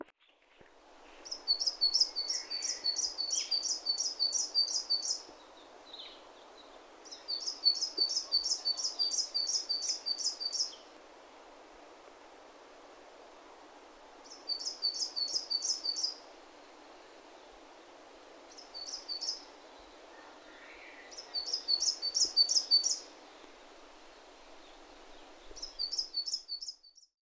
４　ヒガラ（日雀）　全長約11cm
カラ類の中でもっとも小型だが、大きな声で「ツピ、ツピ、ツピ・・・」と早口でさえずる。
【録音8】 　2024年6月6日　東京都高尾山